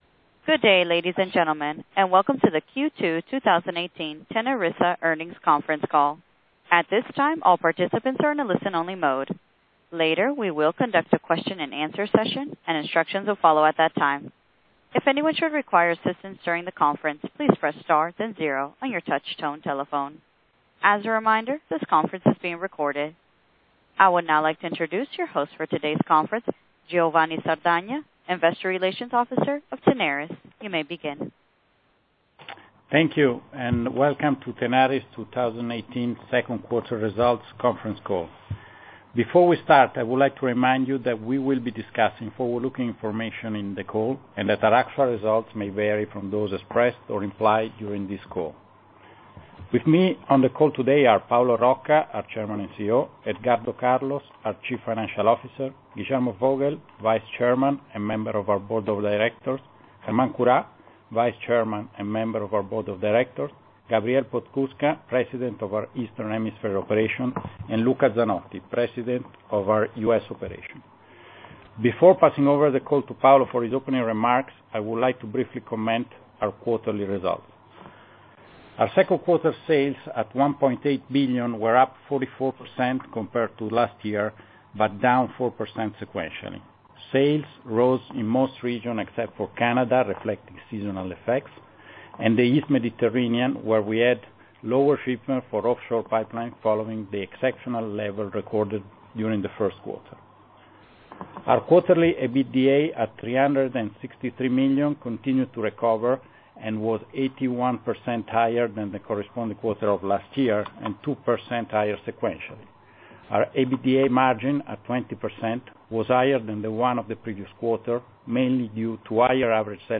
Q2 2018 TENARIS S A Earnings Conference Call.wma